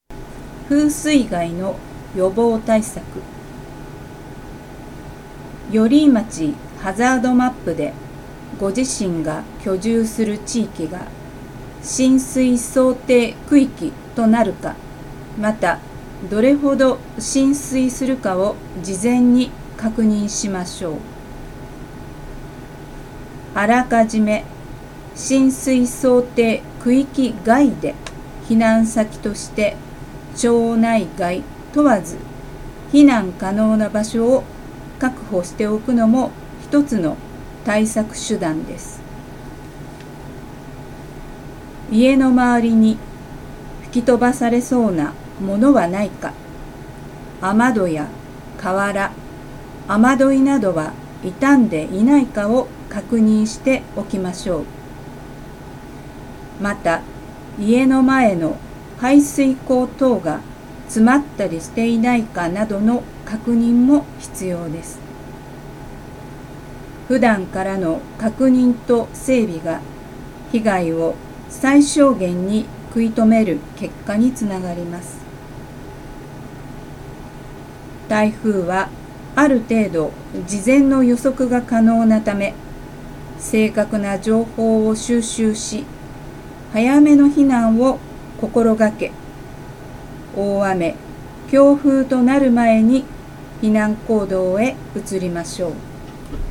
女性消防サポーターによる「音声版ハザードマップ」
女性消防サポーターが、目が不自由な方に向けての防災に関する知識や災害リスク情報を読み上げ、音声で発信します。